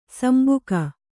♪ sambuka